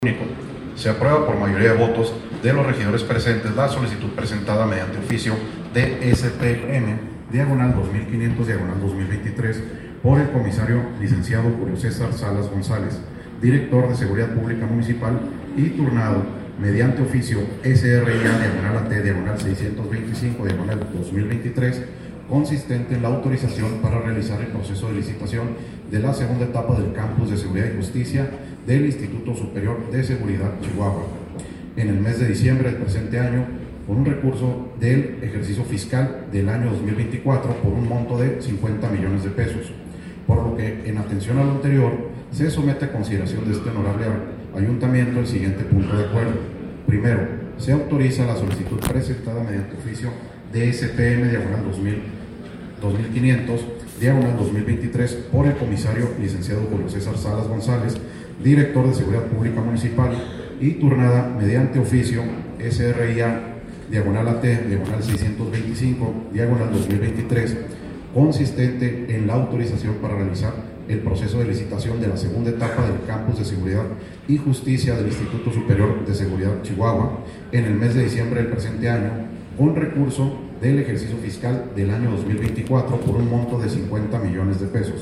Audio: regidor Paco Turati.